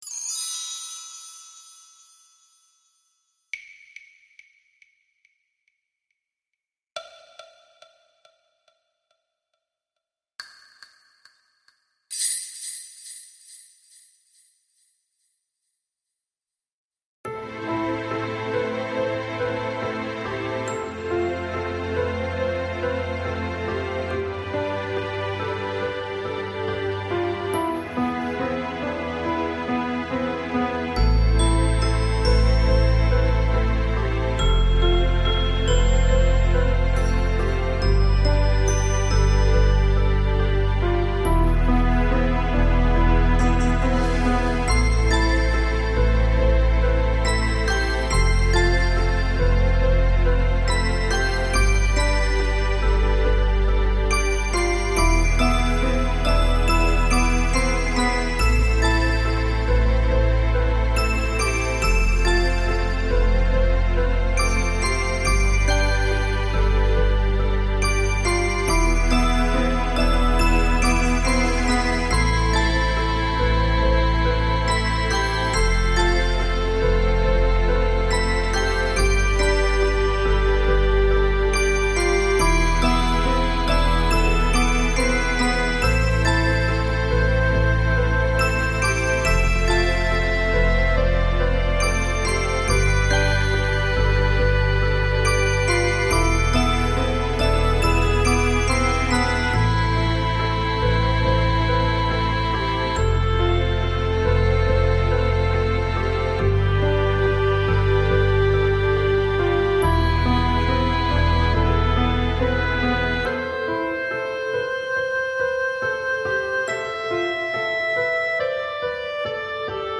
Красивый, мелодичный и трогательный трек.
Стиль: Ambient/Soundtrack